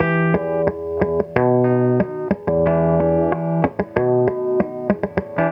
WEIRDLOOP.wav